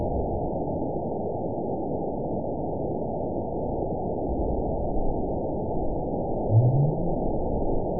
event 920749 date 04/07/24 time 08:24:34 GMT (1 year, 6 months ago) score 9.59 location TSS-AB02 detected by nrw target species NRW annotations +NRW Spectrogram: Frequency (kHz) vs. Time (s) audio not available .wav